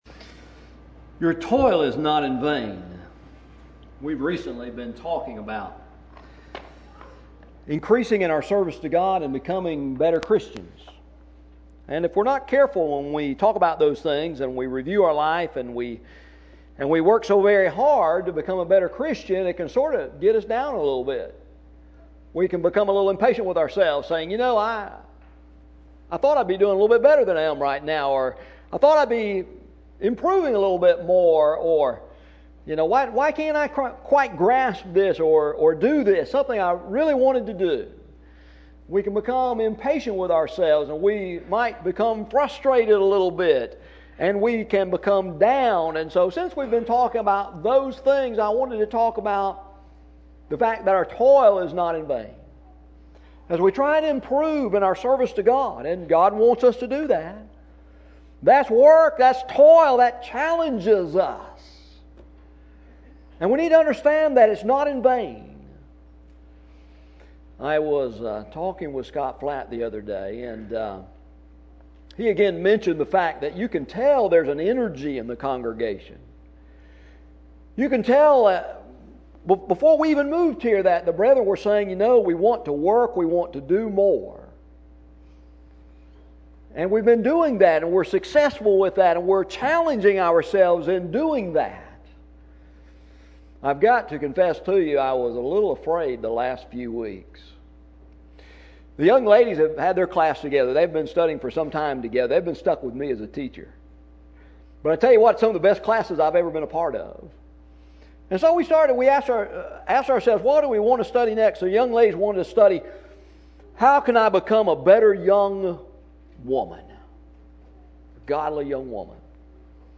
Work: Audio Bible Lessons.